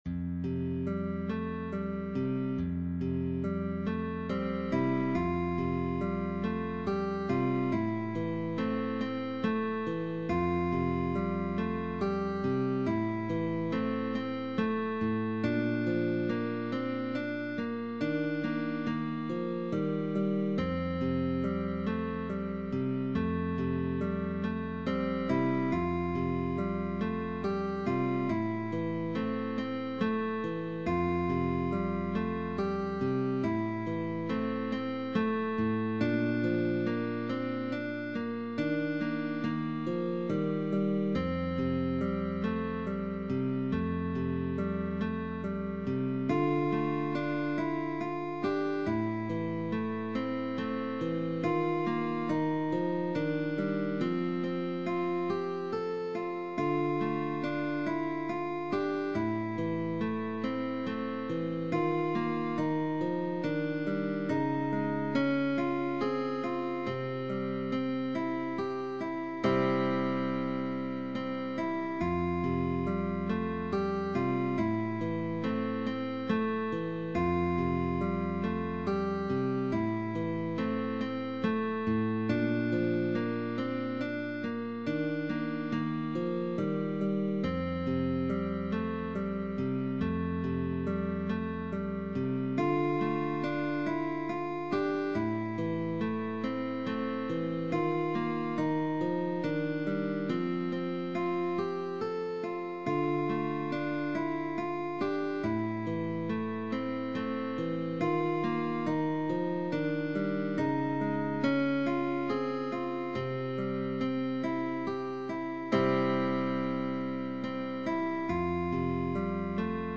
by guitar duo sheetmusic.
MELODIC GUITAR: PUPIL and TEACHER
Rest Stroke. 1st Position.